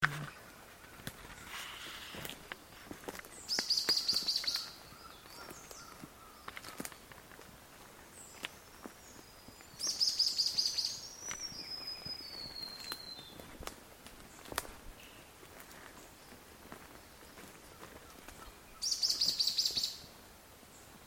Black-billed Scythebill (Campylorhamphus falcularius)
2 ejemplares
Province / Department: Misiones
Location or protected area: Bio Reserva Karadya
Condition: Wild
Certainty: Observed, Recorded vocal